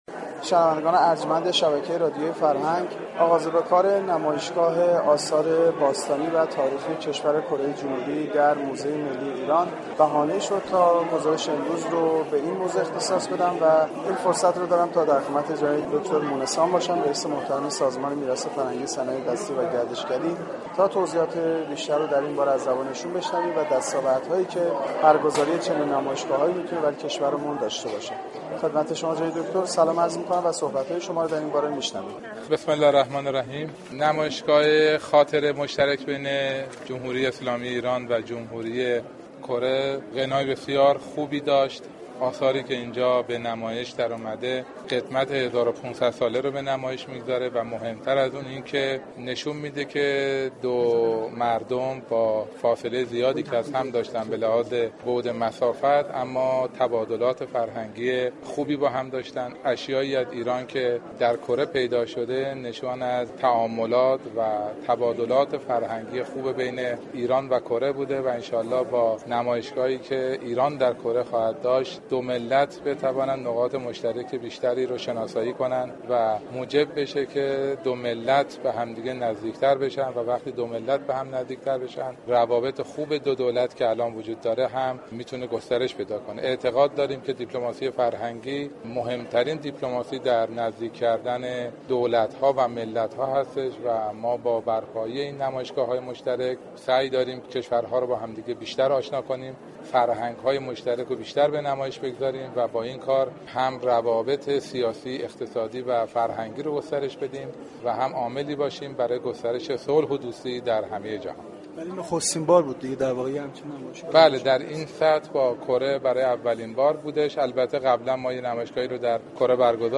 دكتر علی اصغر مونسان در گفتگوی اختصاصی با گزارشگر رادیو فرهنگ ،درباره ی دستاوردهای برگزاری این نمایشگاهها در كشور گفت: نمایشگاه خاطره ی مشترك بین جمهوری اسلامی ایران و جمهوری كره غنای بسیار خوبی دارد .